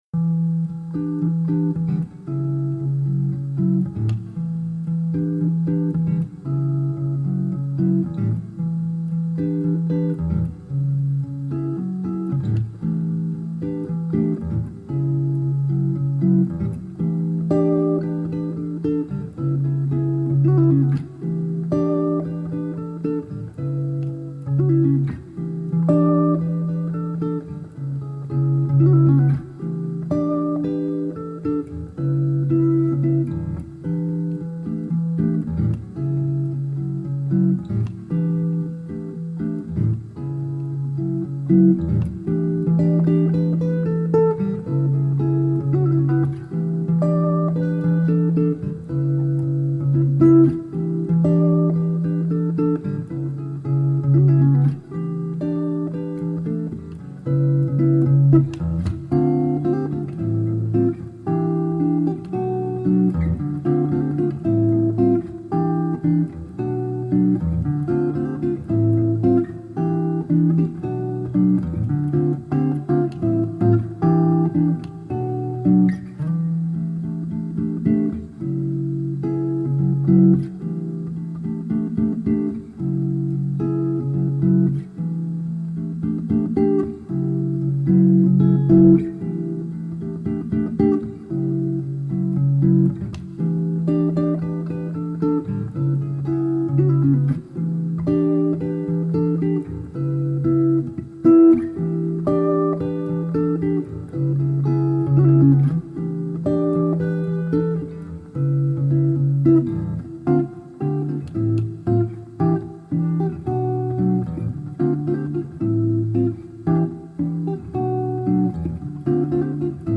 Acoustic new age and jazz guitar..